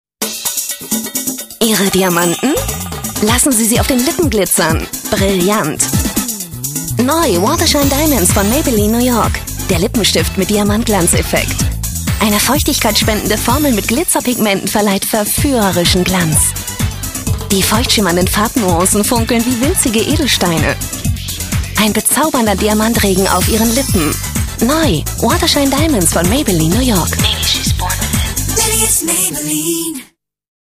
deutsche Sprecherin.
Kein Dialekt
Sprechprobe: Industrie (Muttersprache):
german female voice over talent.